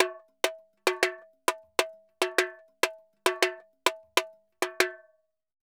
Tamborin Salsa 100_1.wav